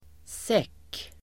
Uttal: [sek:]